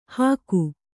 ♪ hāku